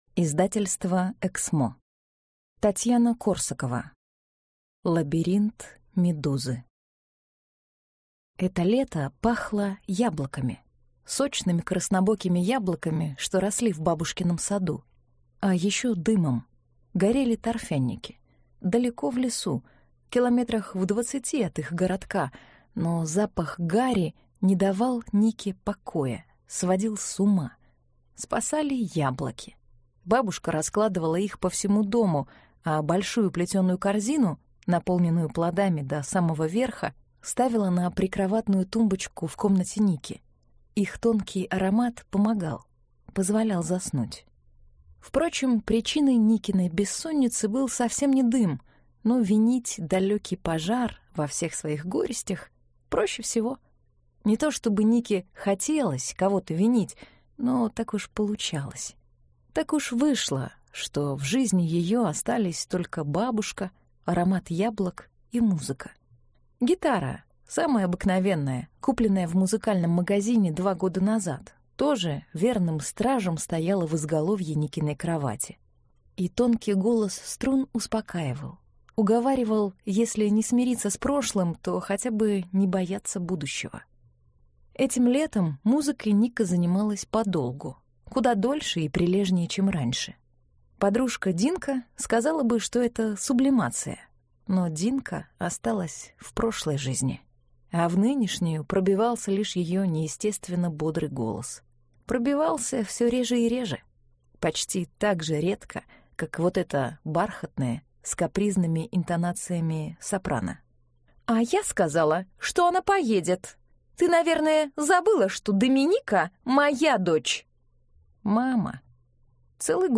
Аудиокнига Лабиринт Медузы - купить, скачать и слушать онлайн | КнигоПоиск